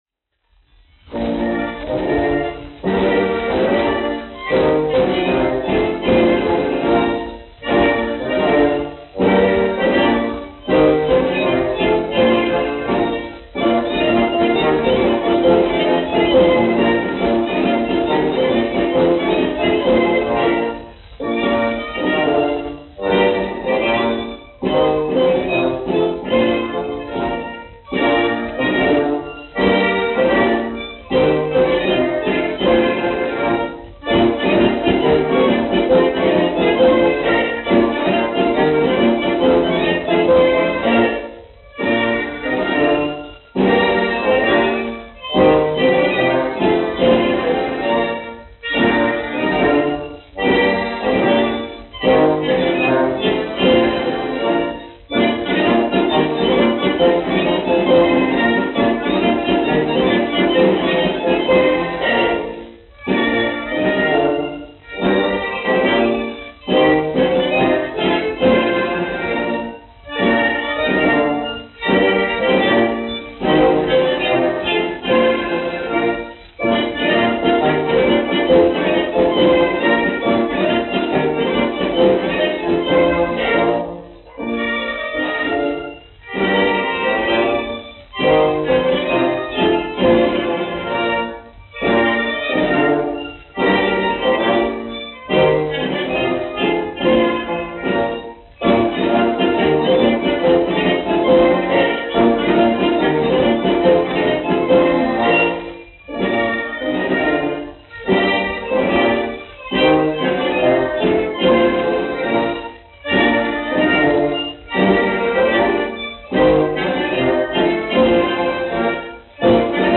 1 skpl. : analogs, 78 apgr/min, mono ; 25 cm
Tautas deju mūzika -- Latvija
Skaņuplate
Latvijas vēsturiskie šellaka skaņuplašu ieraksti (Kolekcija)